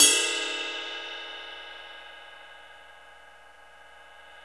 CYM XRIDE 5H.wav